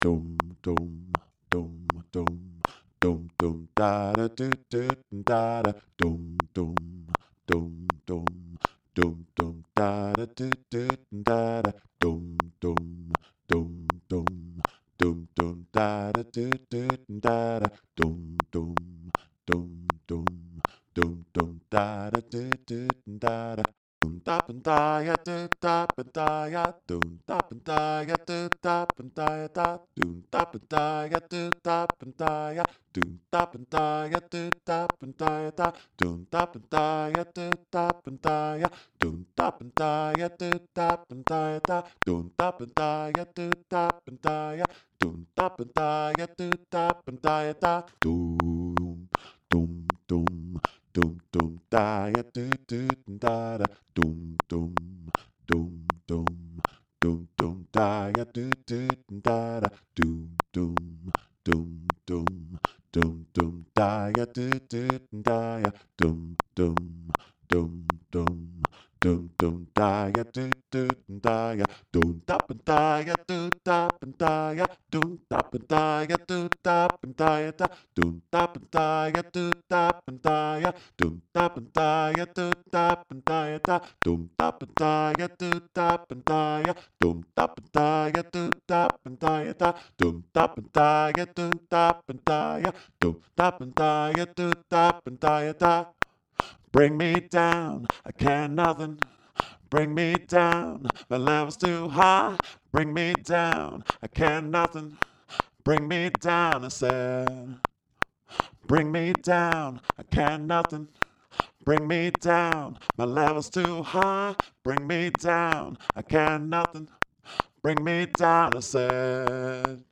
happy-breakaway-bass.mp3